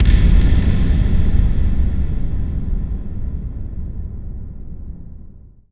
VEC3 FX Reverbkicks 09.wav